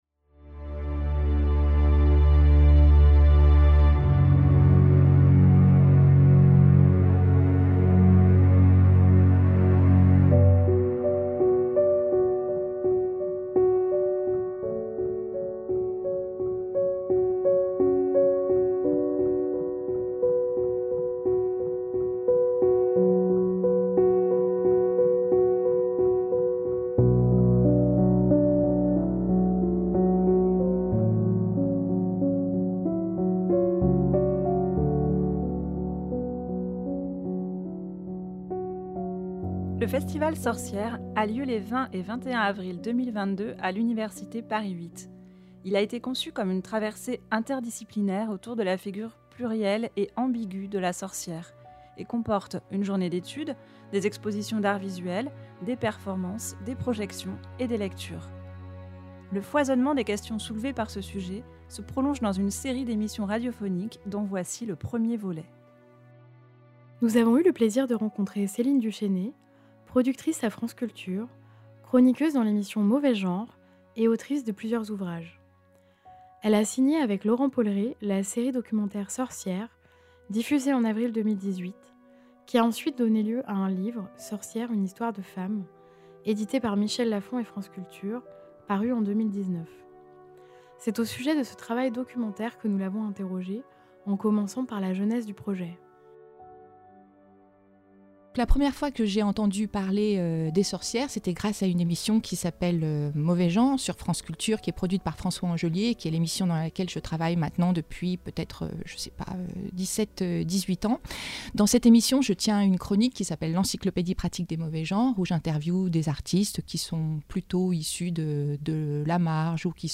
Il a été conçu comme une traversée interdisciplinaire autour de la figure plurielle et ambigüe de la Sorcière, et comporte une journée d’étude, des expositions d’arts visuels, des performances, des projections, des lectures. Le foisonnement des questions soulevées par ce sujet se prolonge dans une série d’émissions radiophoniques dont voici le premier volet.